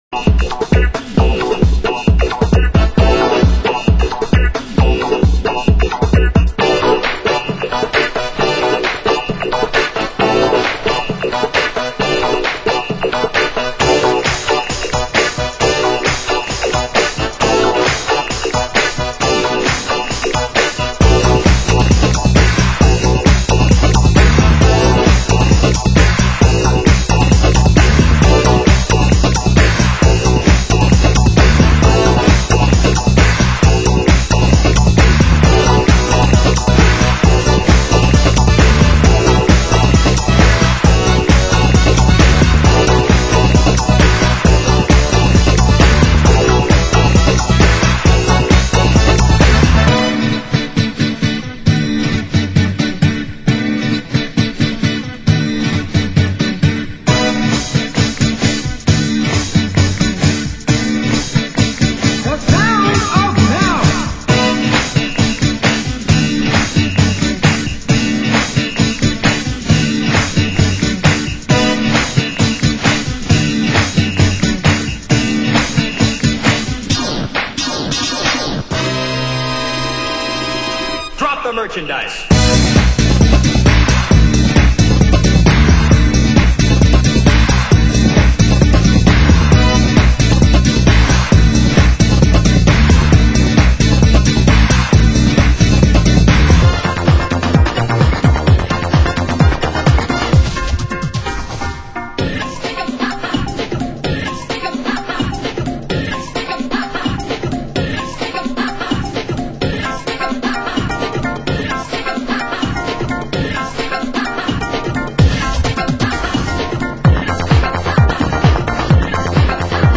Genre Progressive